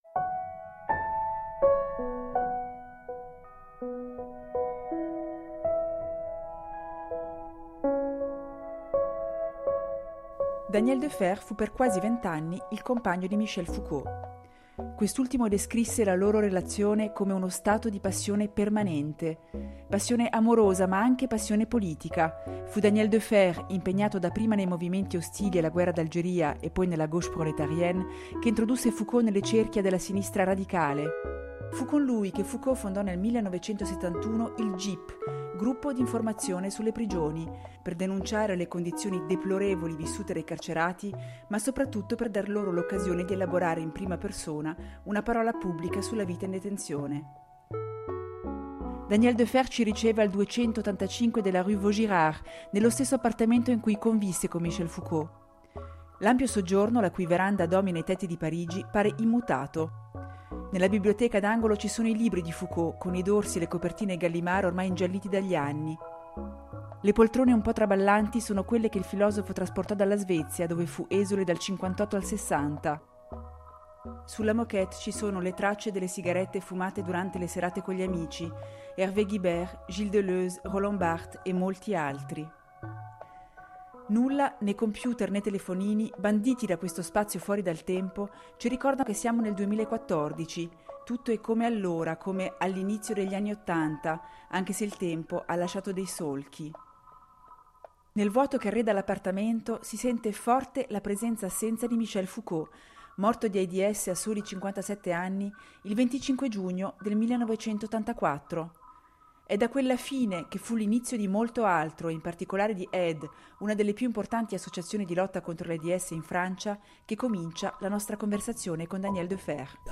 Intervista con Daniel Defert